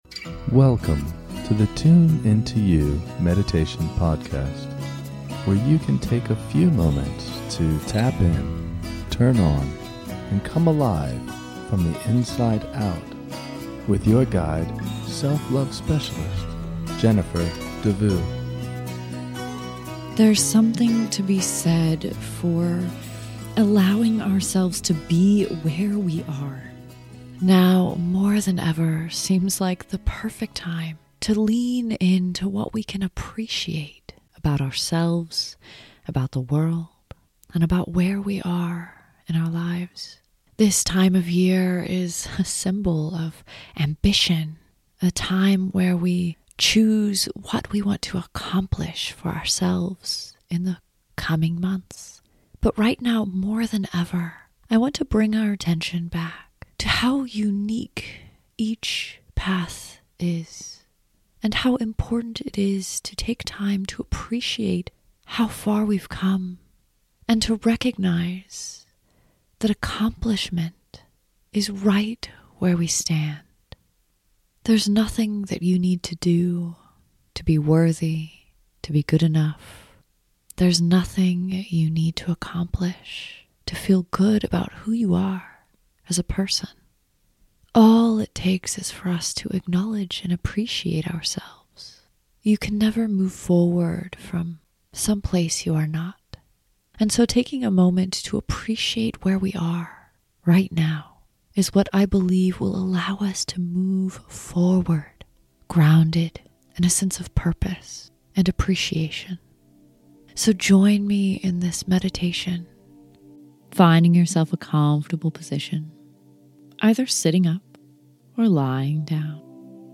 In this guided meditation, we will take a few minutes to relax, center, and ground ourselves in where we are.